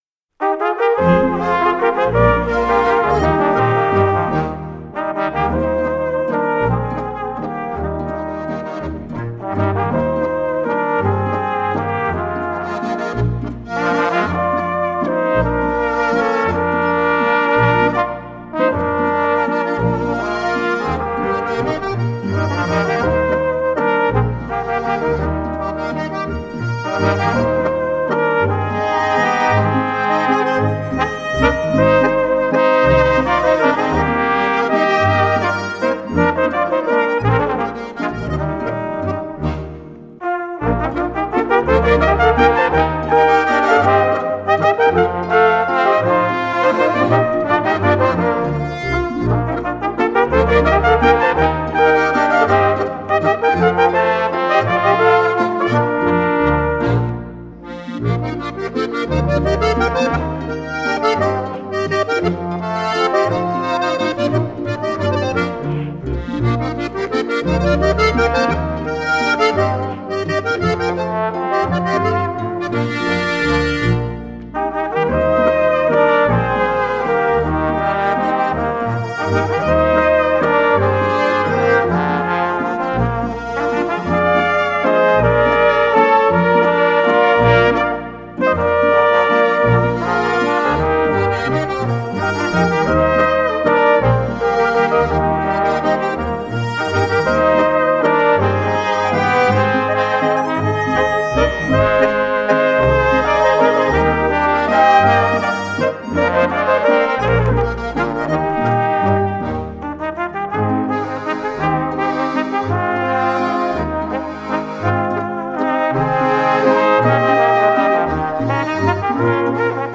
Besetzung: Volksmusik Tanzlmusik
Klarinette in B
1. Flügelhorn in B
Posaune in B/C
Bass in C / Harmonika